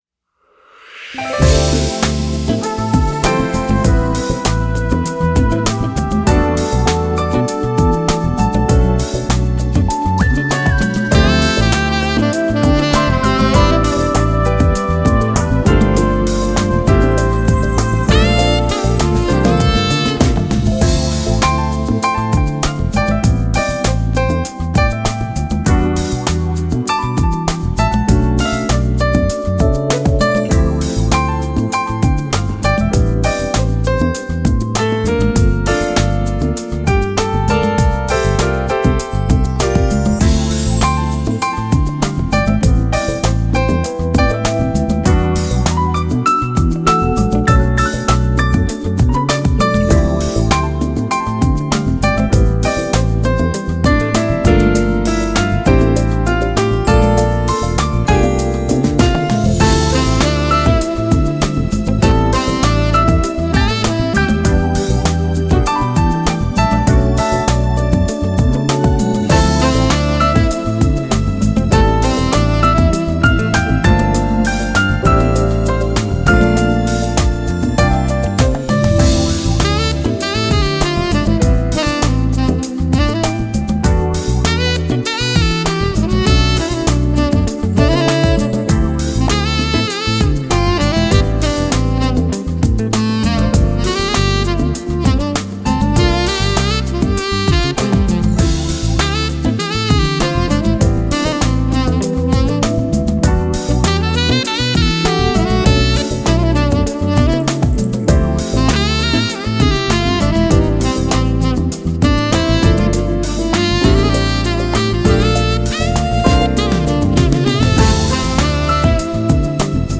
Genre: Smooth Jazz